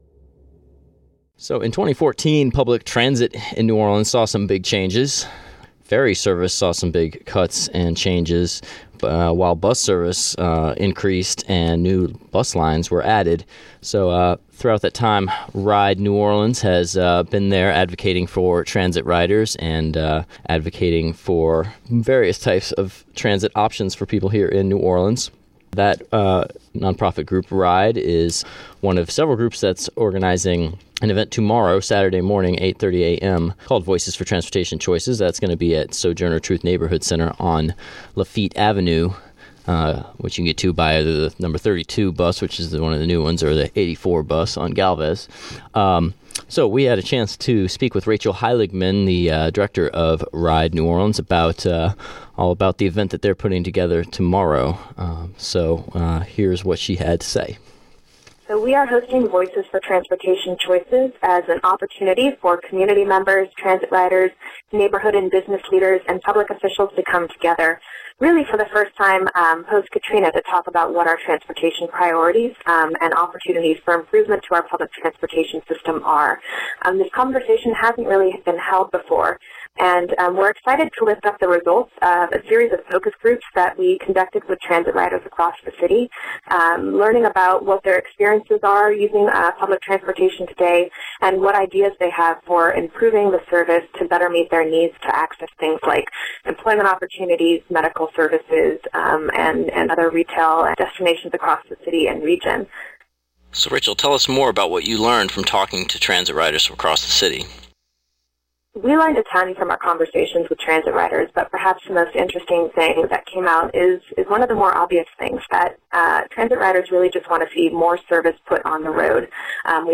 speaks to WTUL New Orleans about the state of public transit today and where it's going in the future.